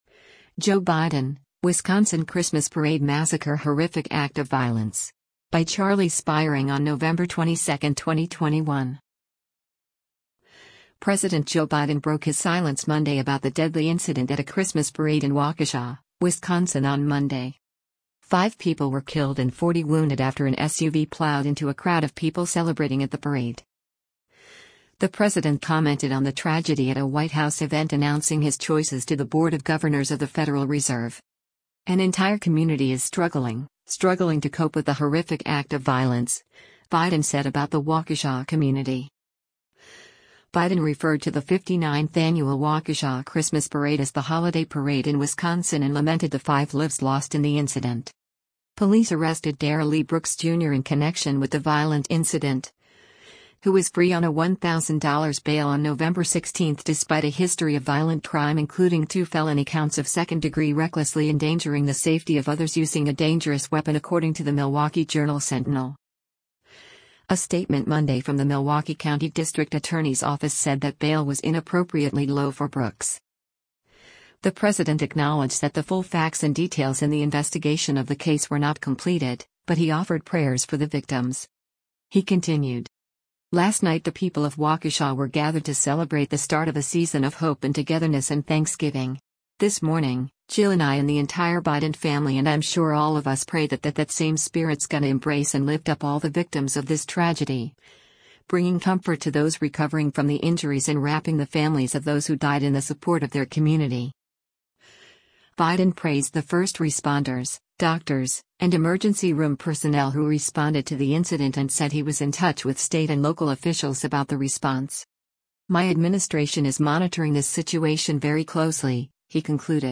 The president commented on the tragedy at a White House event announcing his choices to the Board of Governors of the Federal Reserve.